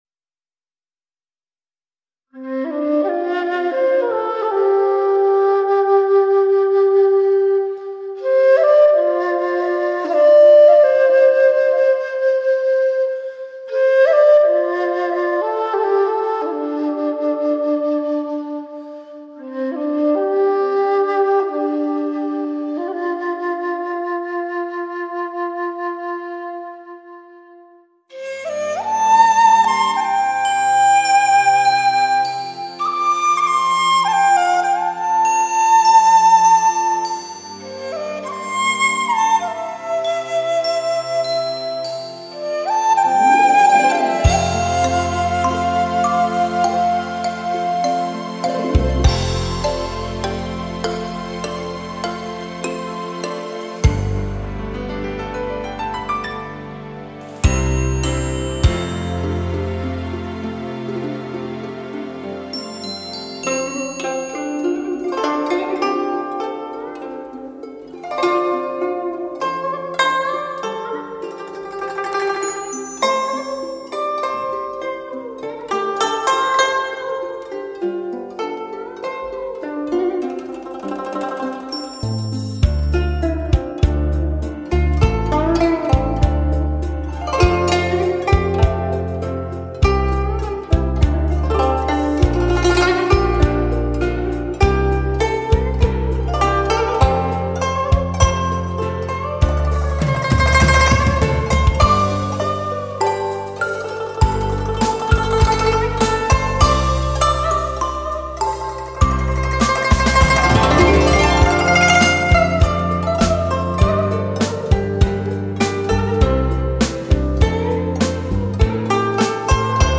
（梅花雪/古筝）